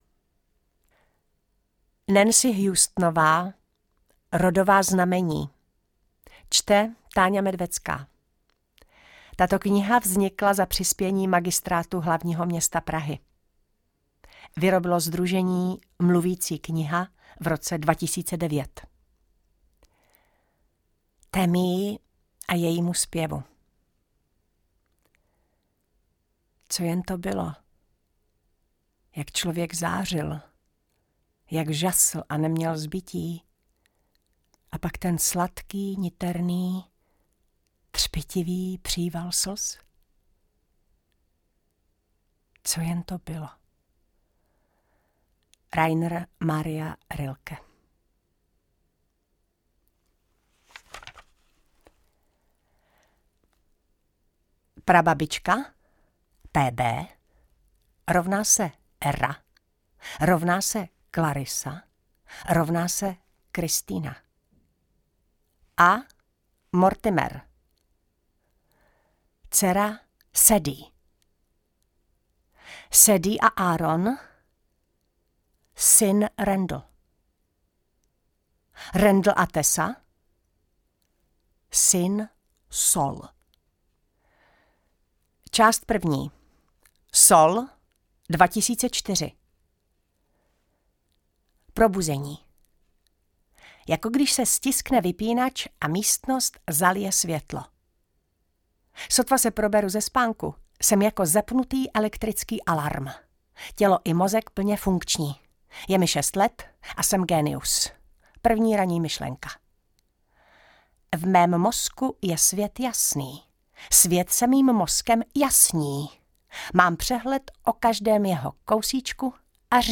Čte: Táňa Medvecká